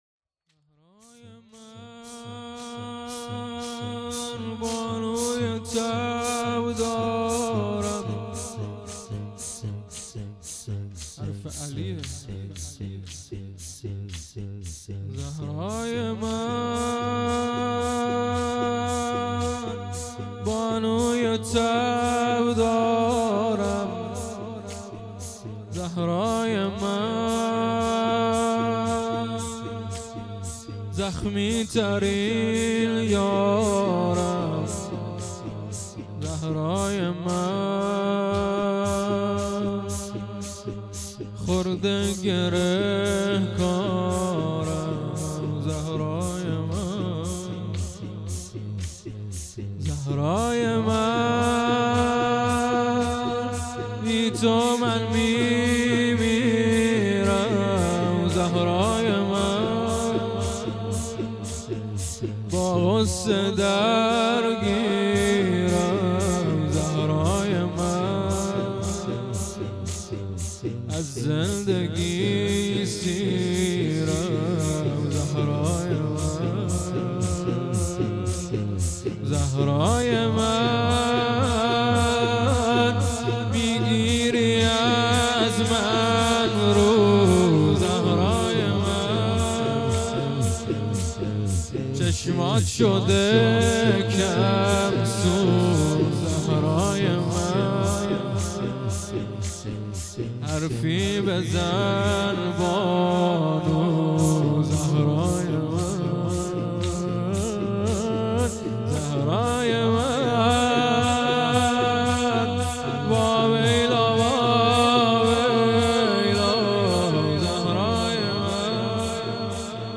پیشواز فاطمیه اول 1442ه.ق دی ماه 1399